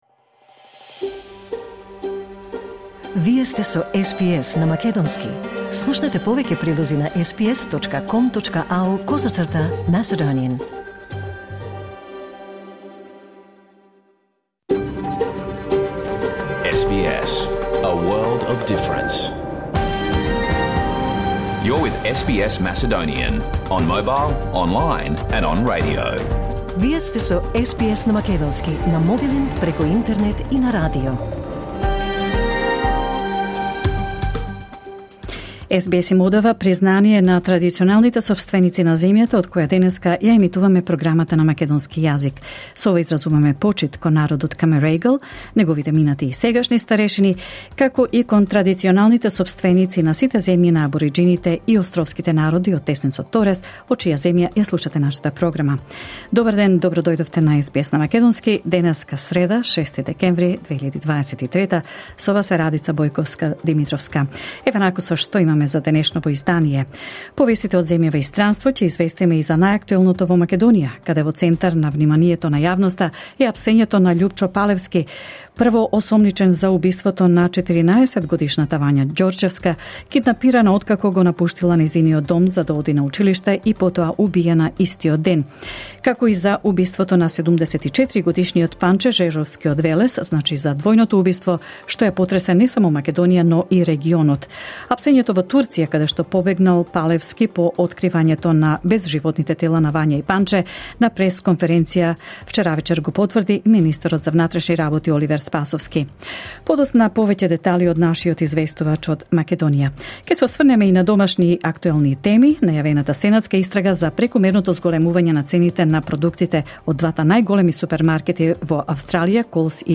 SBS Macedonian Program Live on Air 6 December 2023